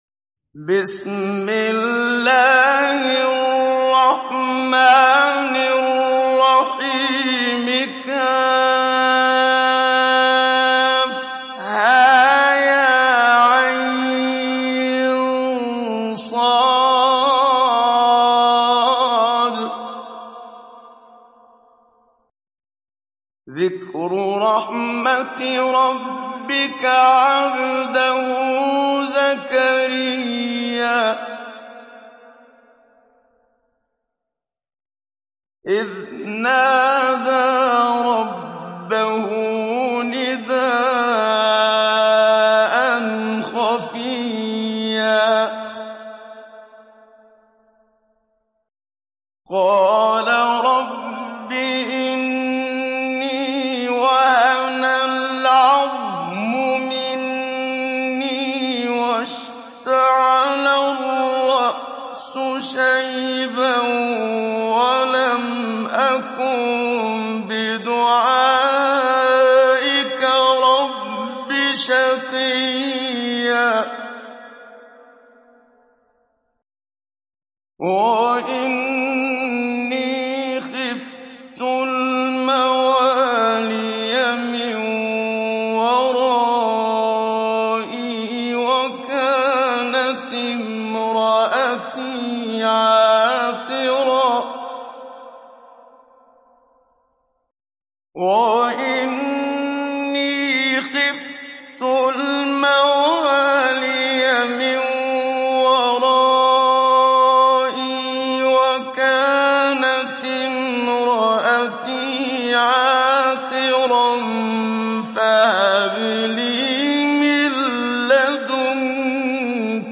Download Surat Maryam Muhammad Siddiq Minshawi Mujawwad